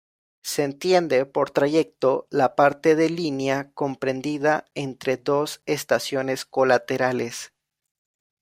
tra‧yec‧to
/tɾaˈʝeɡto/